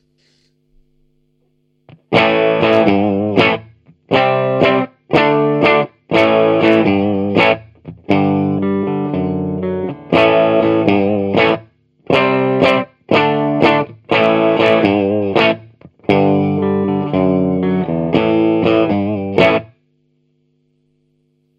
Der Palmer Eins reagiert hochdynamisch auf die Härte deiner Anschläge. Sanft Gezupftes erklingt brav, während heftige Anschläge unmittelbar im Anschluss schon relativ angezerrt werden (je nach Einstellung natürlich).
Boost am Anschlag
Sound angezerrt
palmer_eins_testbericht_sound_angezerrt_.mp3